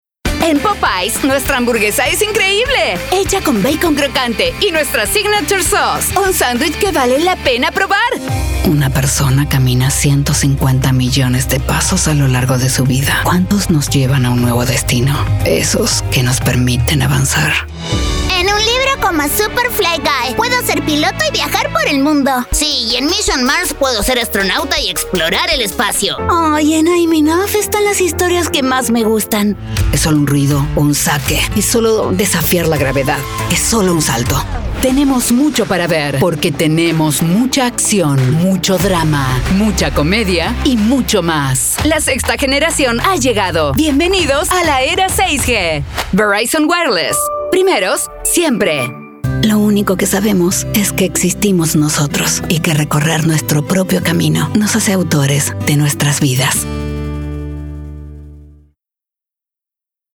Reel
Español Neutro